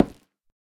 Minecraft Version Minecraft Version 1.21.5 Latest Release | Latest Snapshot 1.21.5 / assets / minecraft / sounds / block / nether_wood / break1.ogg Compare With Compare With Latest Release | Latest Snapshot